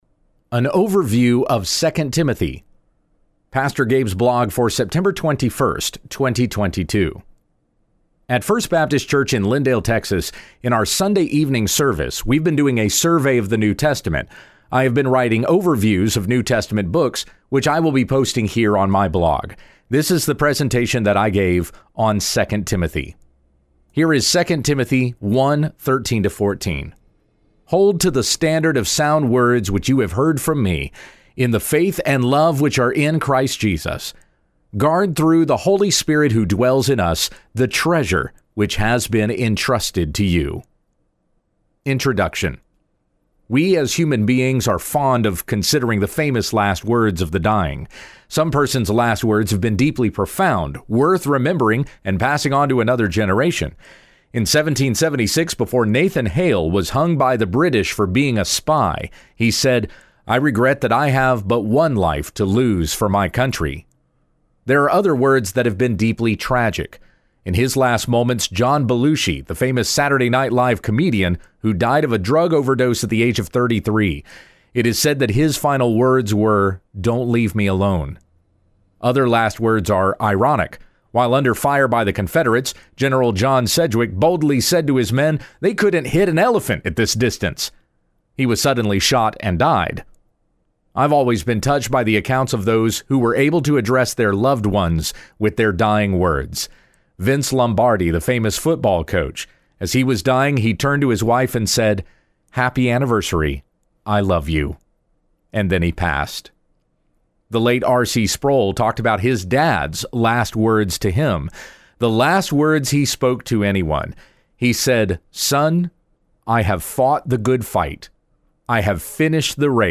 At First Baptist Church in Lindale, TX, in our Sunday evening service, we have been doing a Survey of the New Testament.